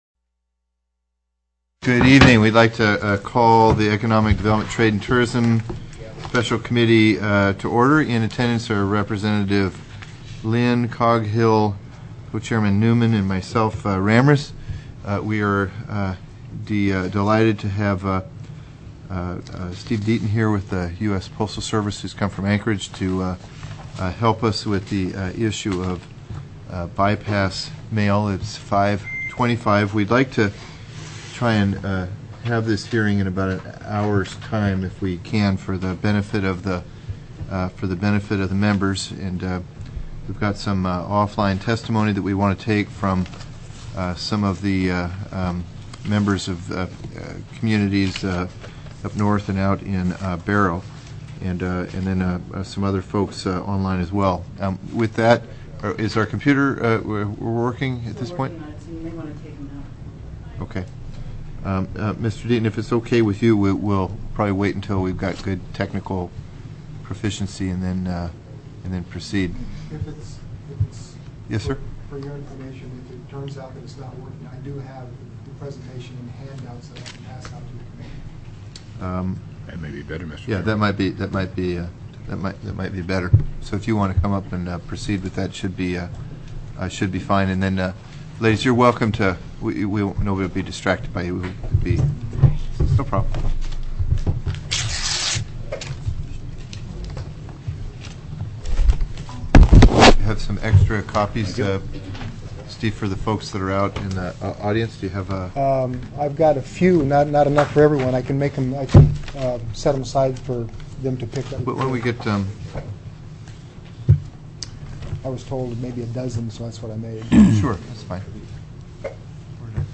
04/19/2006 05:15 PM House ECONOMIC DEV., TRADE, AND TOURISM